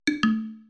fallback-bloop.mp3